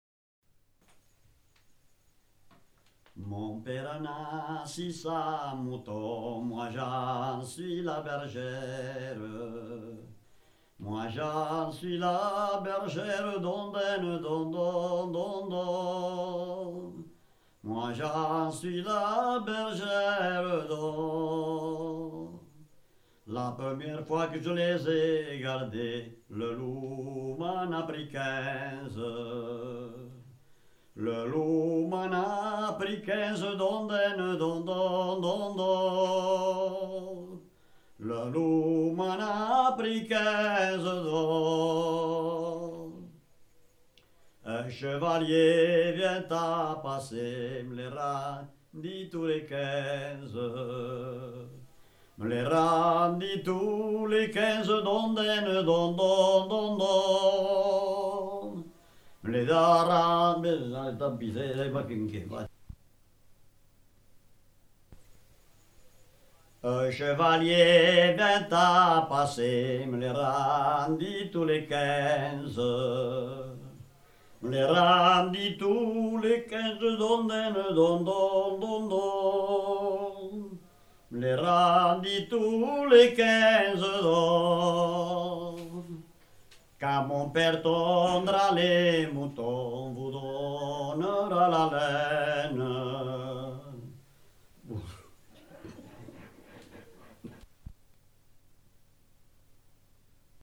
Lieu : Ségalas (lieu-dit)
Genre : chant
Effectif : 1
Type de voix : voix d'homme
Production du son : chanté